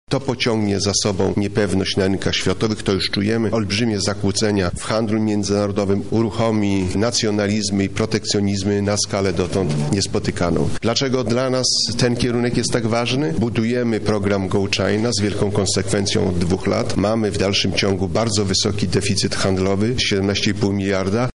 – Zbliża się poważne trzęsienie ziemi – mówi Janusz Piechociński, minister gospodarki